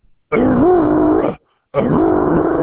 howling roar sound.
RoaringSnark.wav